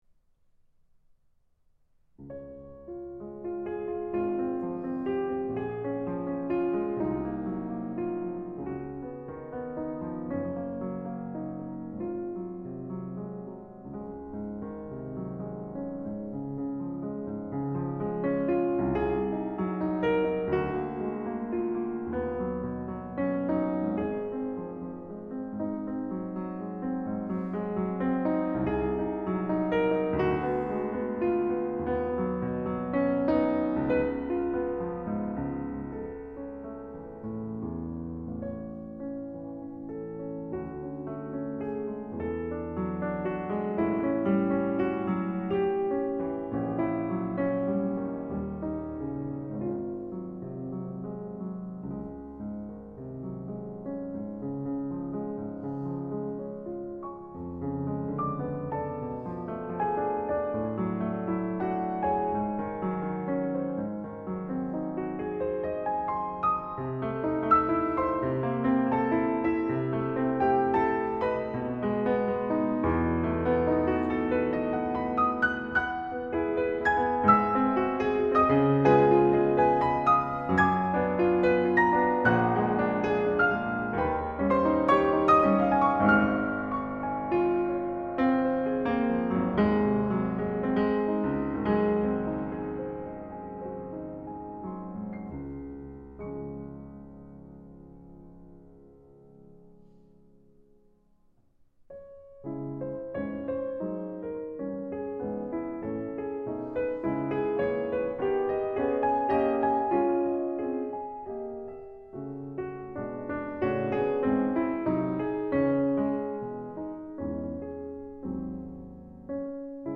Japanese pianist